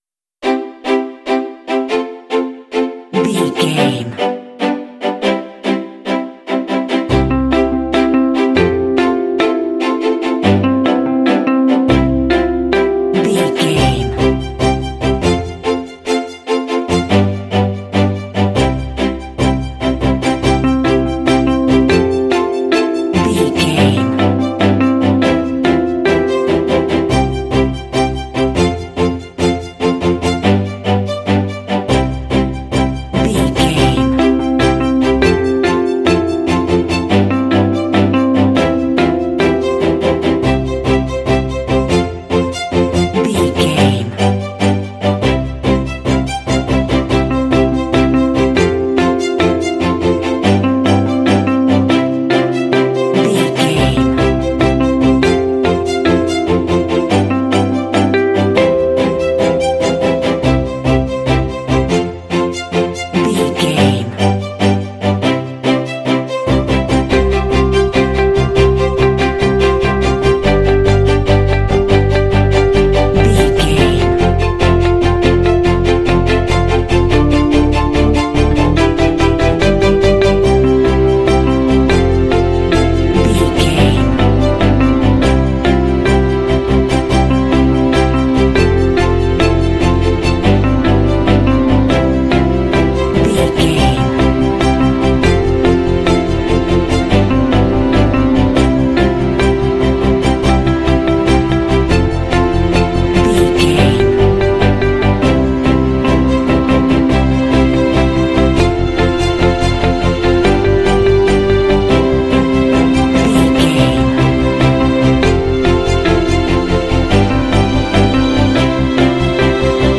Ionian/Major
happy
joyful
strings
harp
bass guitar
contemporary underscore